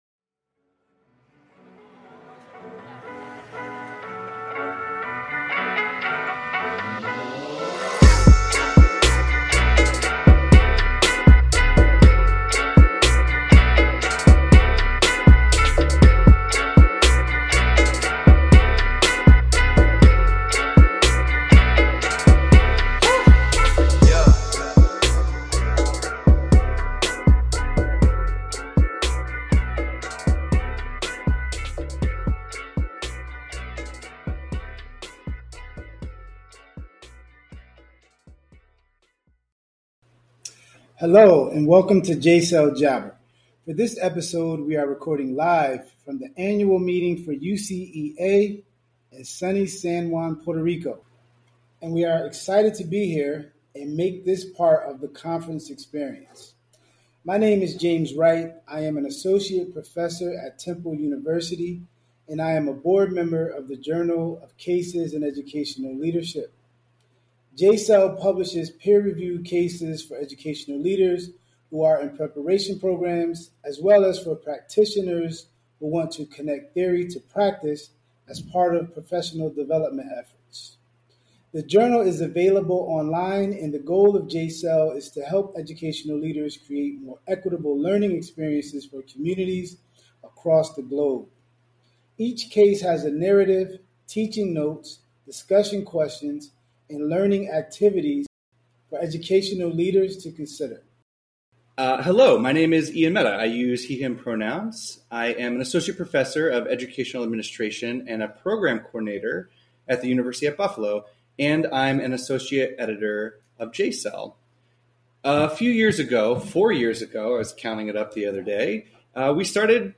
The episode was recorded live at the annual meeting of the University Council for Educational Administration in November of 2025.